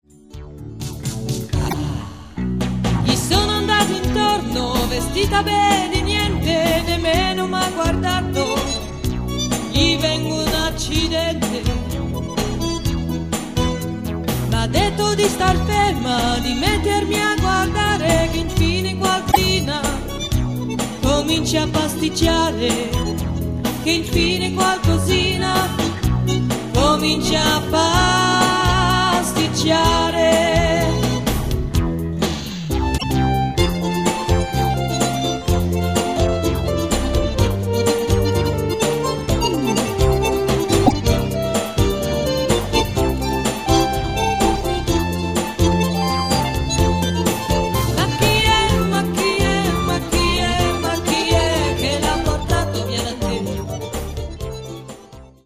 extraits live du spectacle en solo